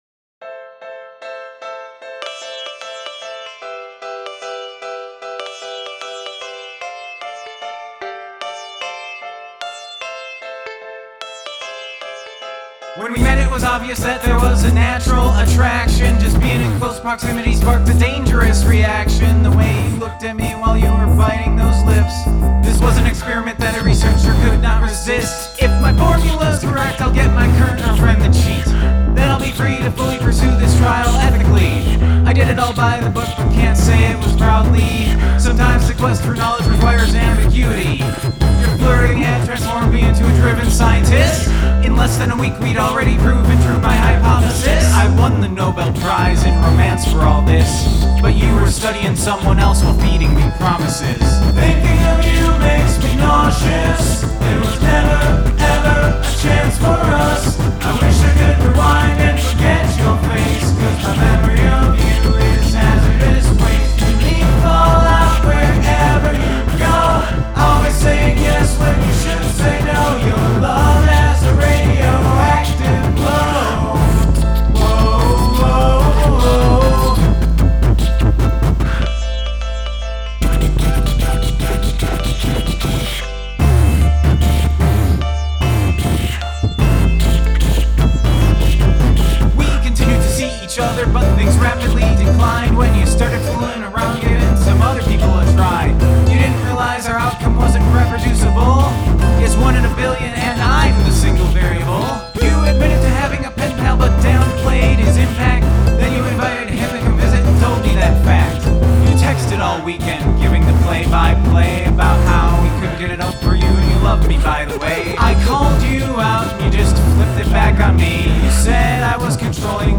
Beatbox